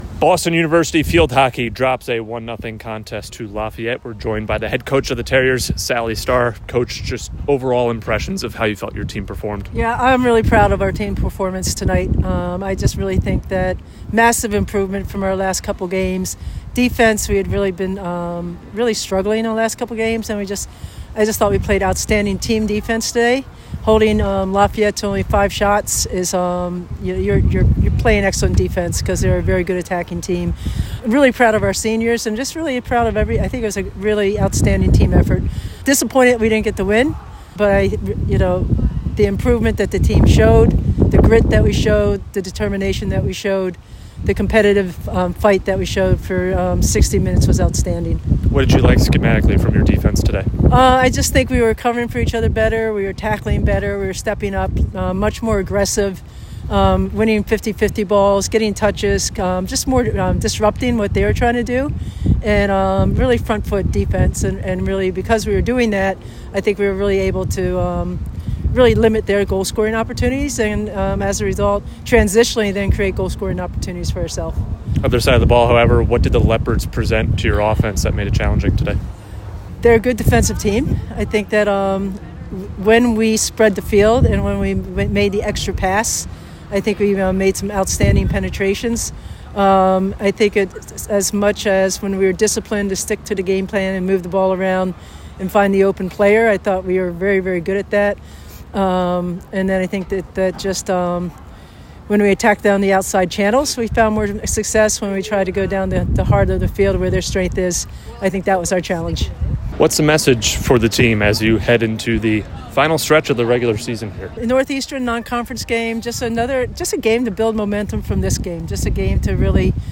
FH_Lafayette_Postgame.mp3